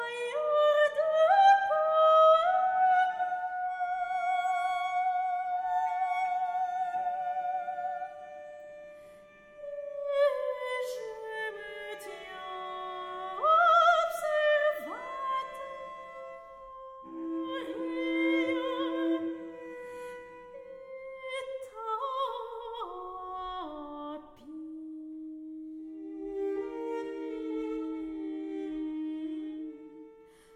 Musique Classique